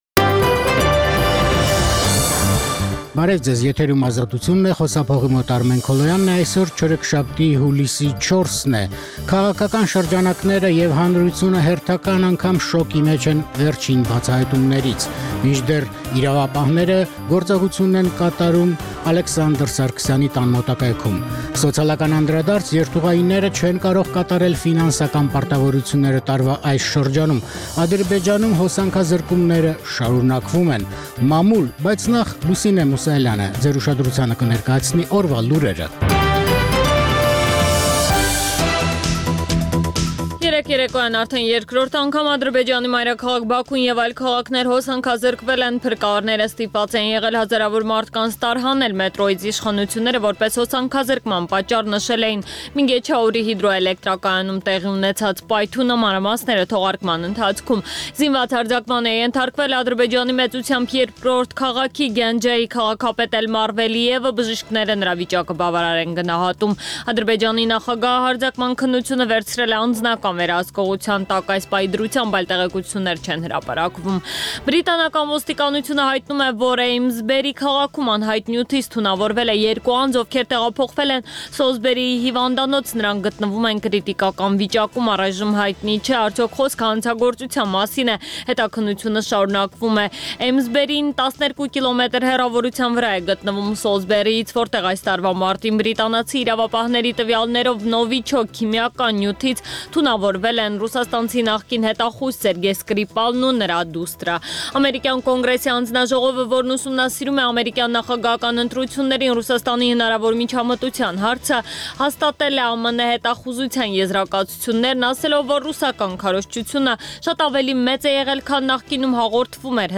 «Ազատություն» ռադիոկայանի ցերեկային ծրագիր